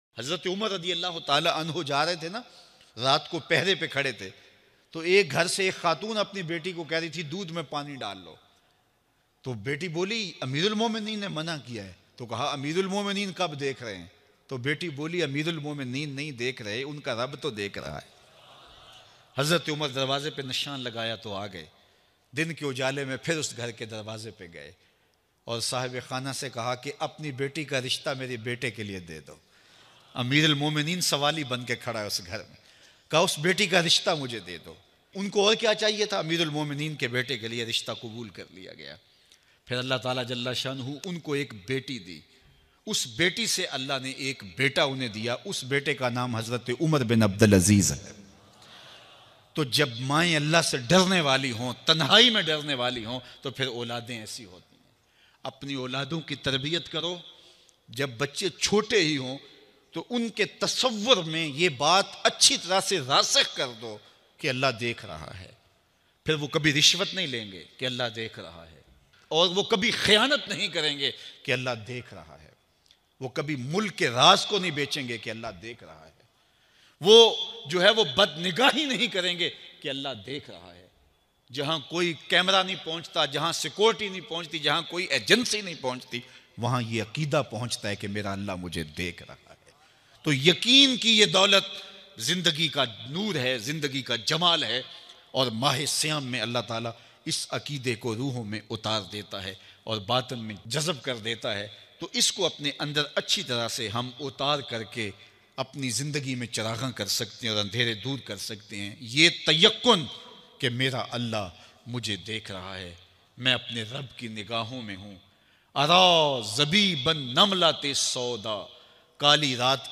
Hazrat UMER RA Larki Ka Rishta Mangne gye bayan mp3